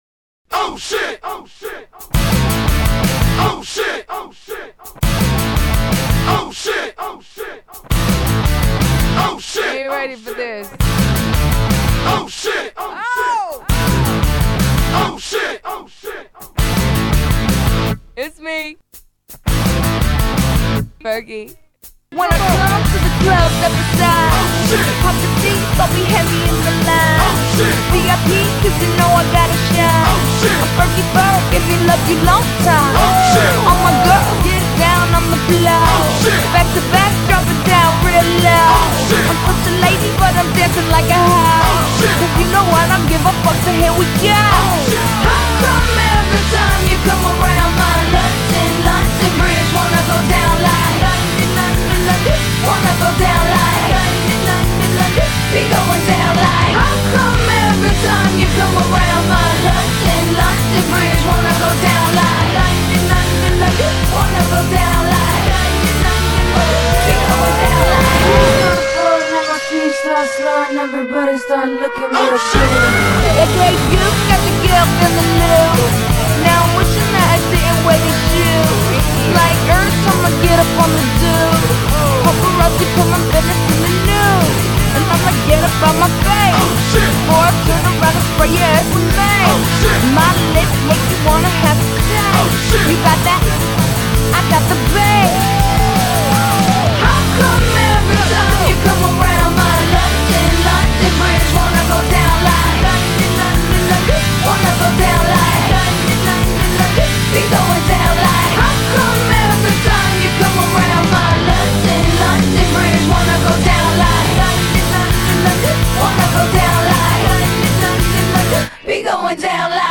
Bootlegs (page 3):